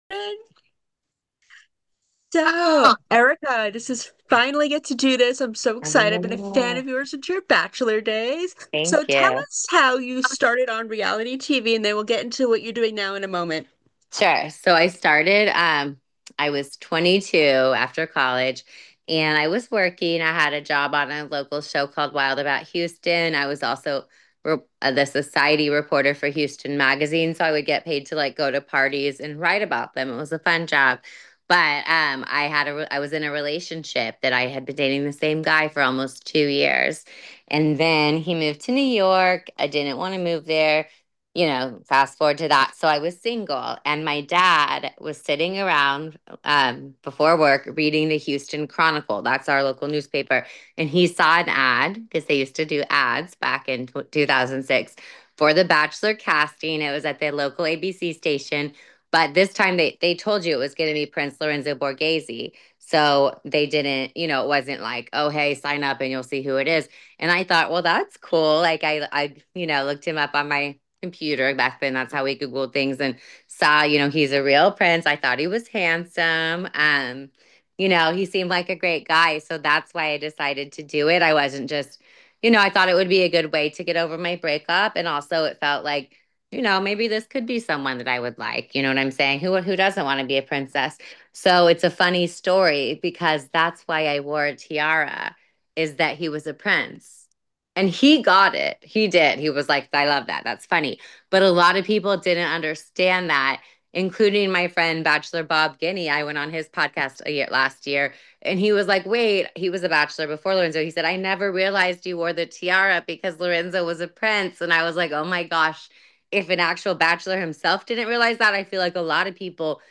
I am so honored to have had the chance to talk to her in a recent interview.